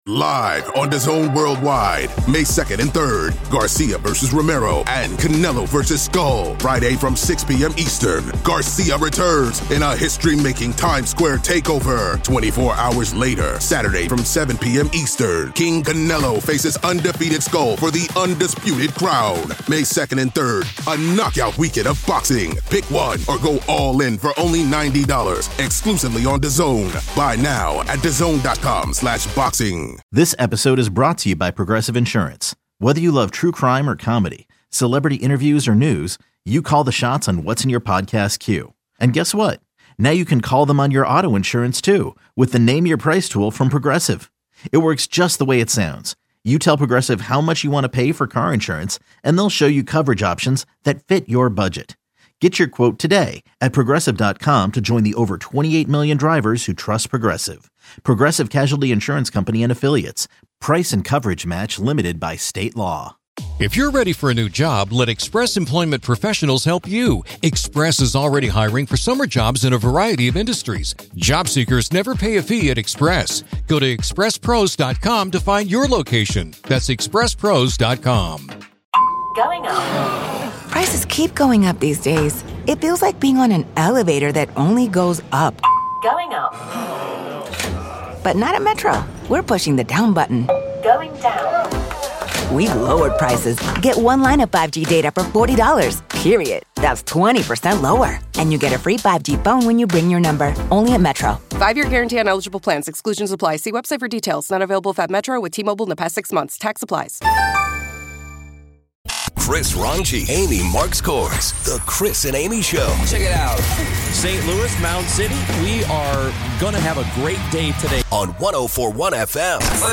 No political agendas--balanced conversations. It's the conversation you and your friends are having on the radio.